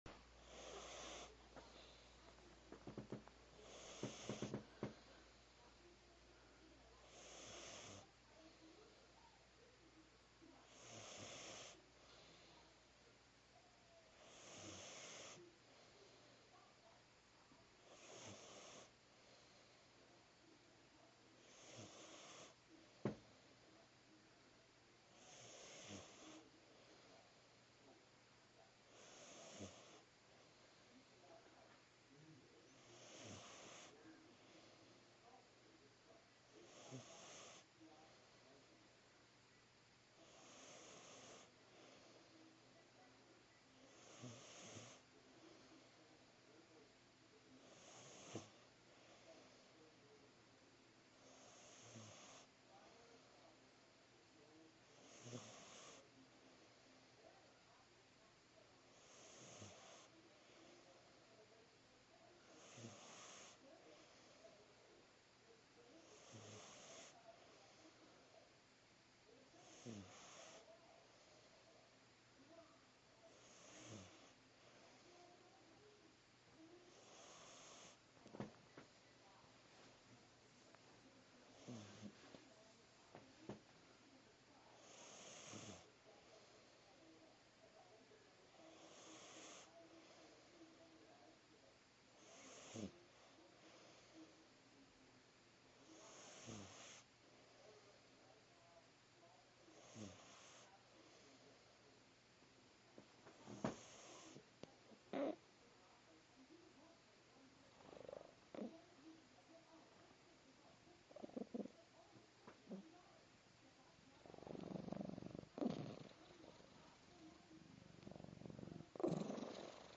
...and purring, and meowing randomly.
The creaking sound you can occasionally hear is me moving around on an old wooden bed that's been around practically as long as I have.